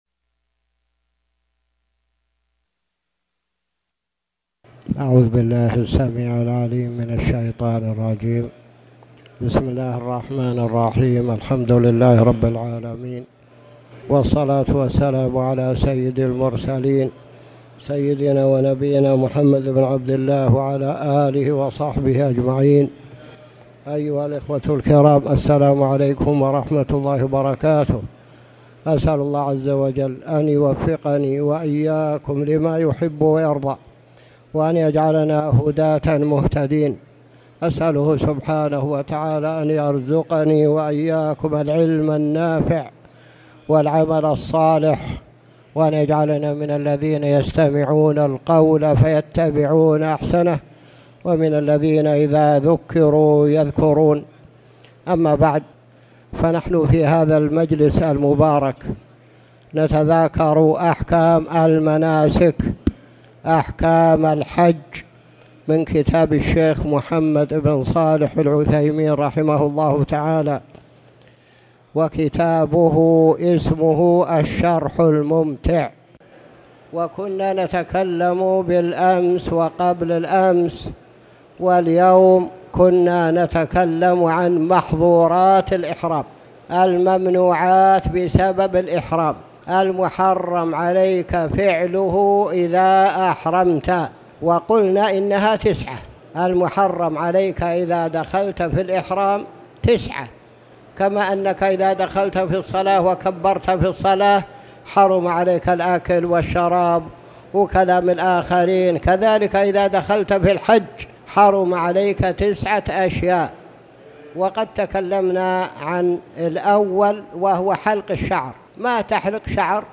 تاريخ النشر ٢٤ ذو القعدة ١٤٣٨ هـ المكان: المسجد الحرام الشيخ